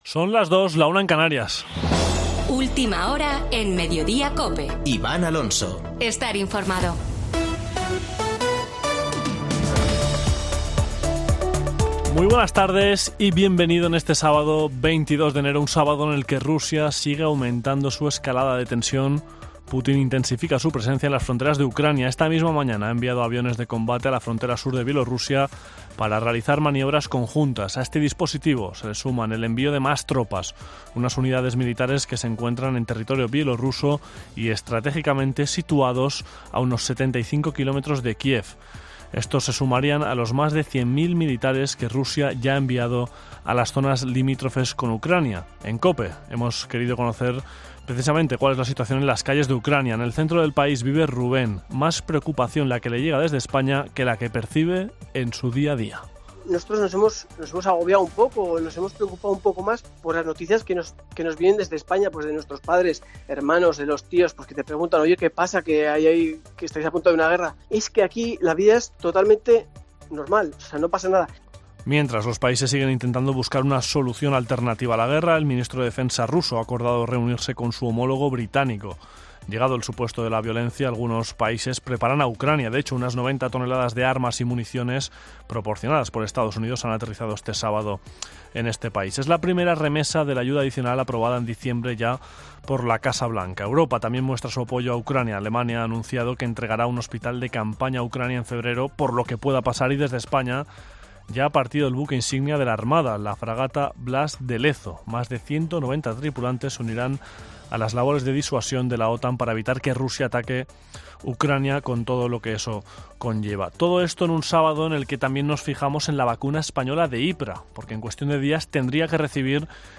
Boletín de noticias COPE del 22 de enero de 2022 a las 14.00 horas